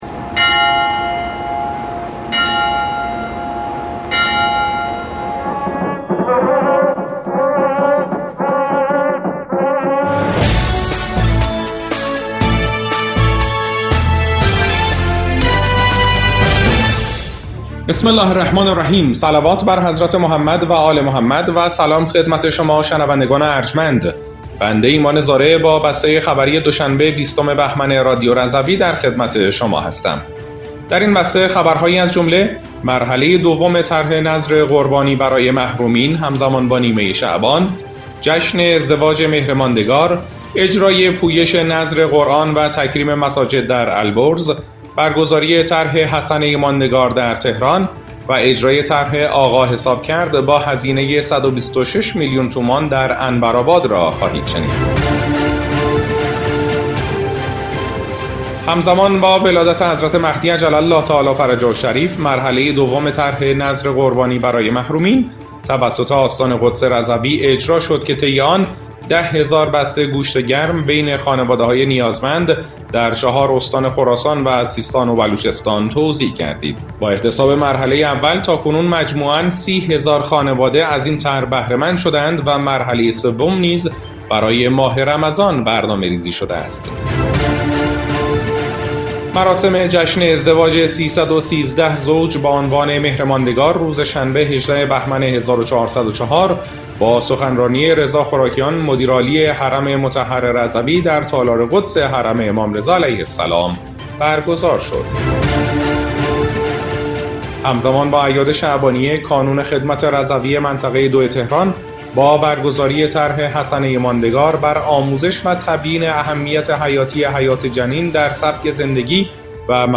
بسته خبری ۲۰ بهمن ۱۴۰۴ رادیو رضوی؛